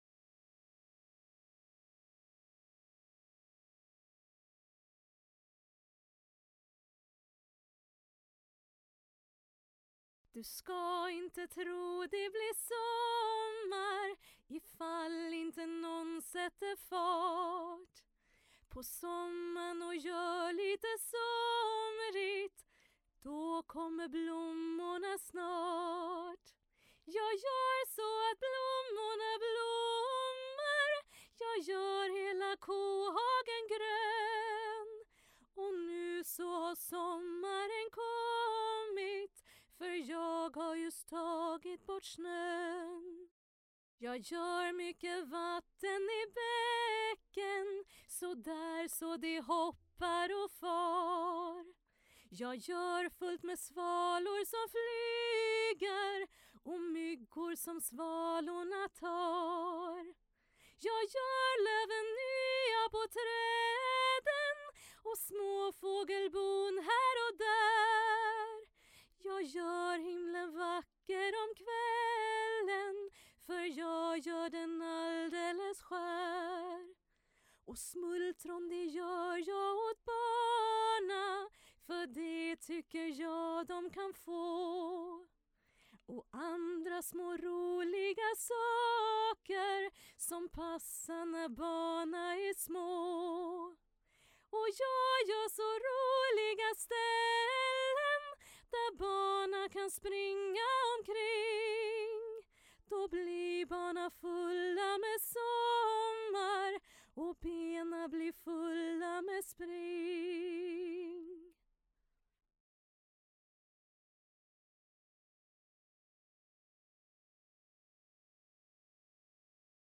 Sologesang